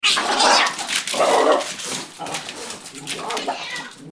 دانلود صدای گربه و سگ در کوچه از ساعد نیوز با لینک مستقیم و کیفیت بالا
جلوه های صوتی